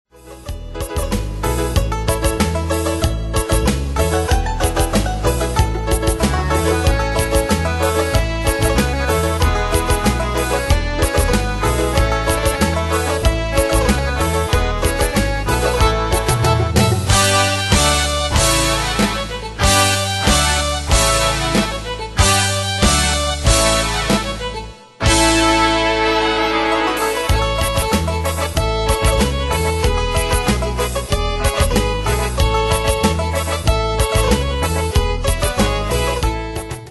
Style: PopFranco Année/Year: 1978 Tempo: 94 Durée/Time: 2.56
Danse/Dance: Cajun Cat Id.
Pro Backing Tracks